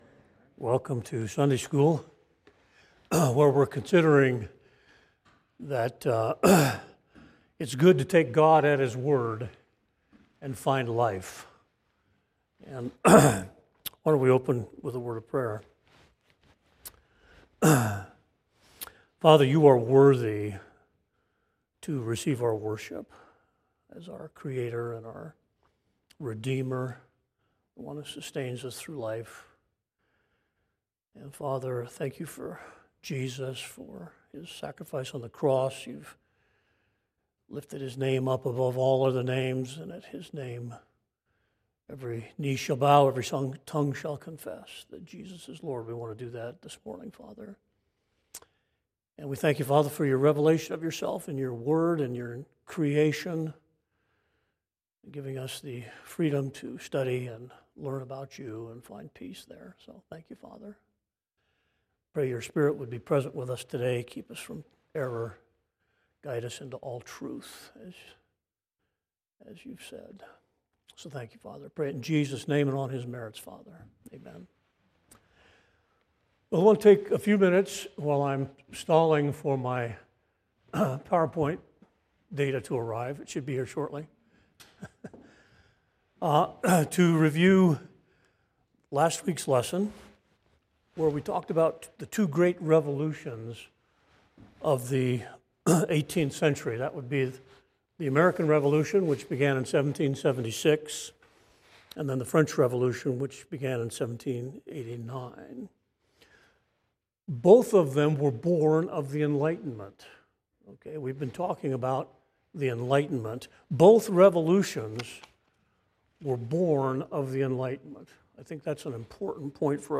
Lesson 7 (Sunday School)